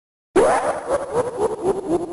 Sound Effects
Sonic.Exe Laugh